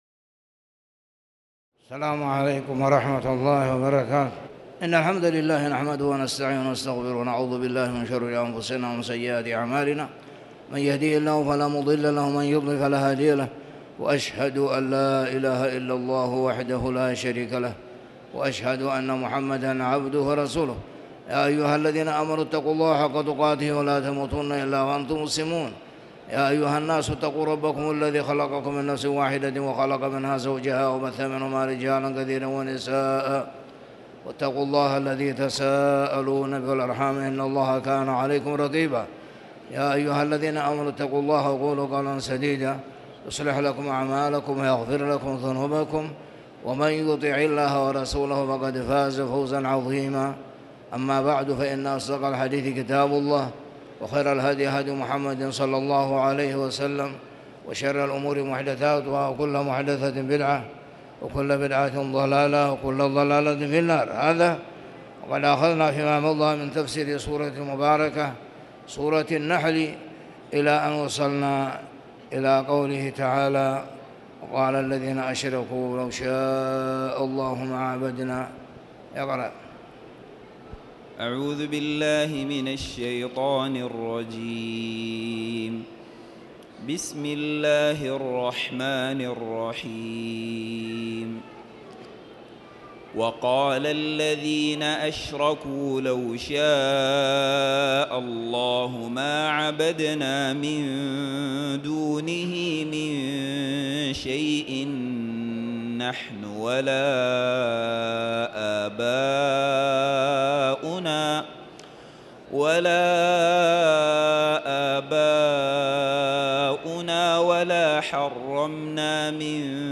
تاريخ النشر ٣ صفر ١٤٣٨ هـ المكان: المسجد الحرام الشيخ